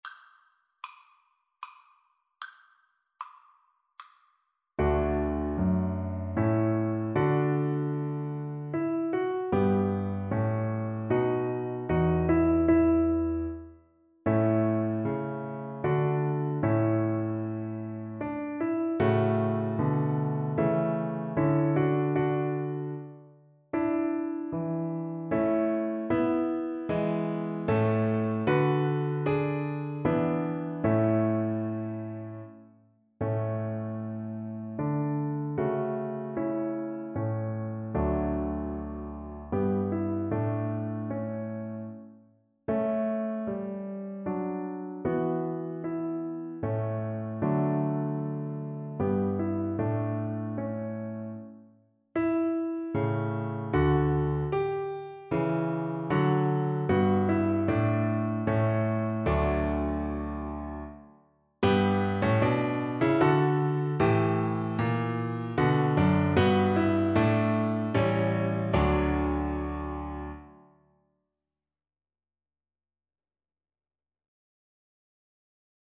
Play (or use space bar on your keyboard) Pause Music Playalong - Piano Accompaniment Playalong Band Accompaniment not yet available transpose reset tempo print settings full screen
Cello
D major (Sounding Pitch) (View more D major Music for Cello )
3/4 (View more 3/4 Music)
Feierlich, doch nicht zu langsam = 76